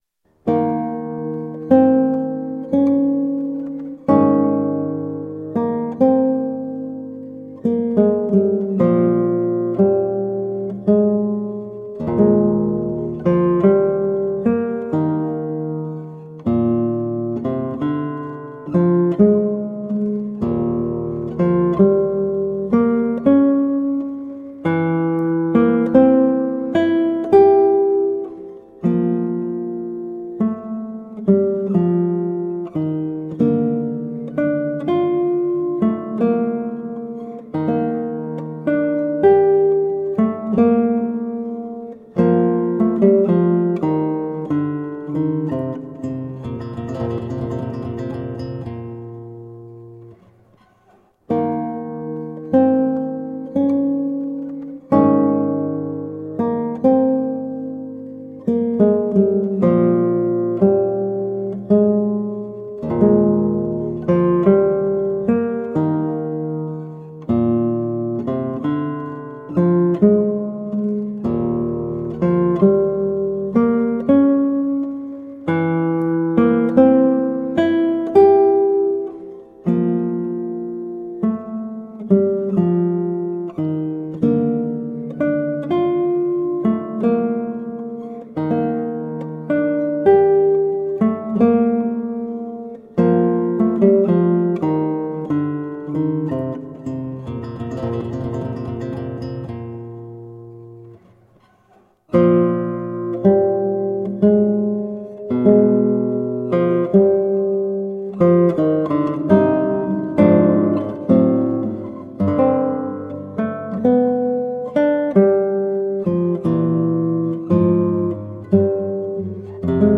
Colorful classical guitar.
Classical, Baroque, Instrumental
Classical Guitar